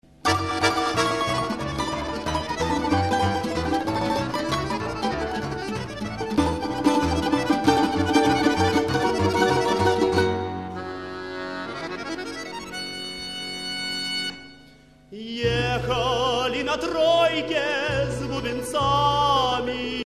fisarmonica
domra
balalaika contrabbasso